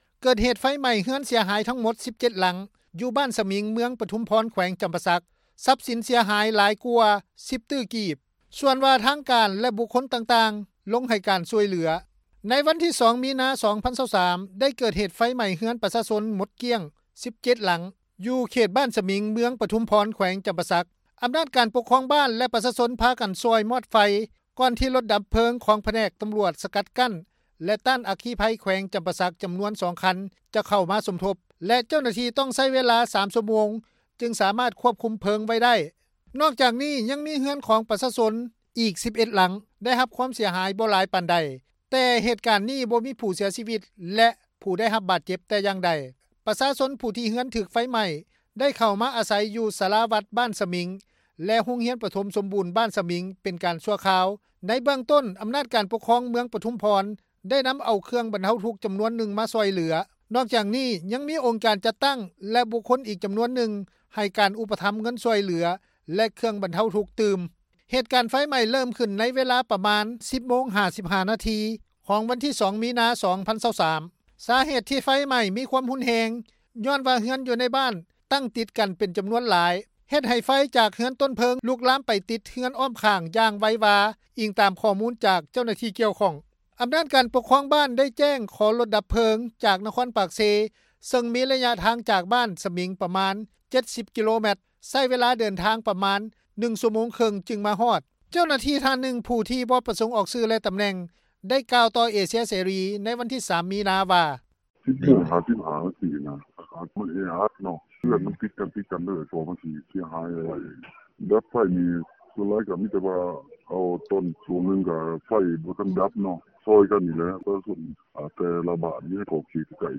ເຈົ້າໜ້າທີ່ທ່ານນຶ່ງ ຜູ້ບໍ່ປະສົງອອກຊື່ ແລະຕຳແໜ່ງ ໄດ້ກ່າວຕໍ່ວິທຍຸ ເອເຊັຽເສຣີ ໃນວັນທີ 3 ມີນາ ວ່າ:
ຊາວບ້ານ ໄດ້ກ່າວຕໍ່ເອເຊັຽເສຣີ ໃນມື້ວັນດຽວກັນນີ້ວ່າ: